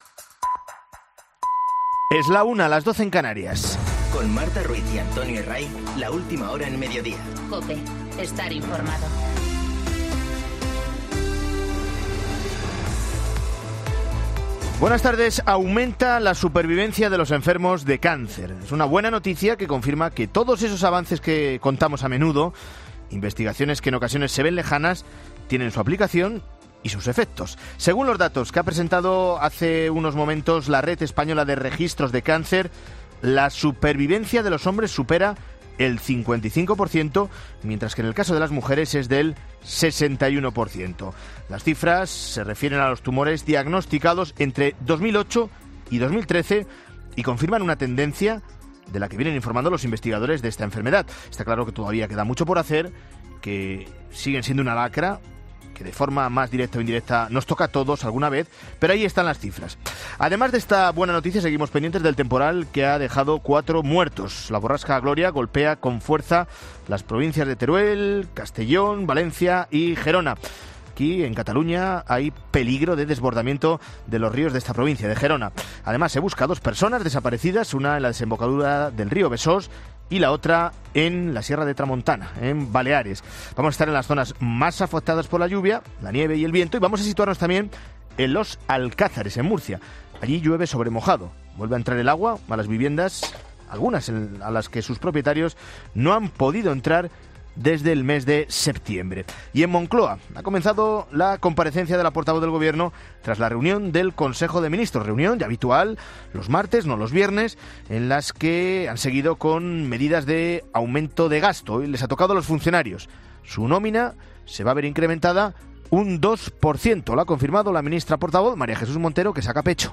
Boletín de noticias COPE del 21 de enero a las 13.00